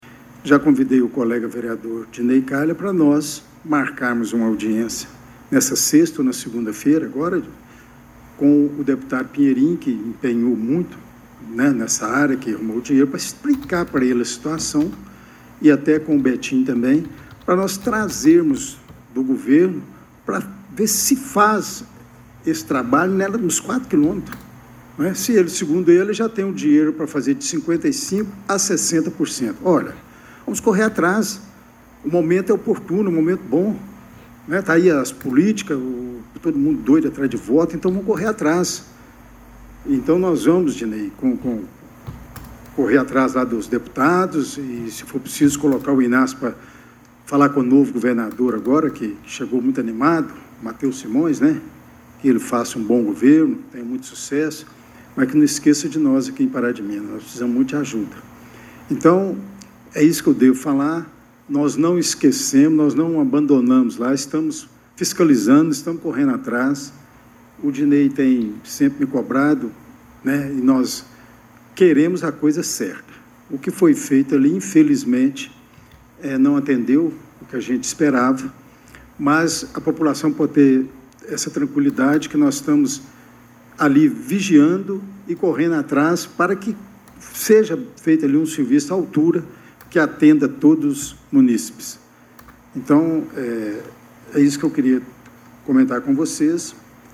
Vereador admite em pronunciamento na tribuna que obra na BR 352 “infelizmente não atendeu o que a gente esperava” – Portal GRNEWS
A reunião ordinária da Câmara Municipal de Pará de Minas, realizada nesta terça-feira, 24 de março, trouxe à tona o descontentamento com a qualidade das obras de infraestrutura rodoviária no município.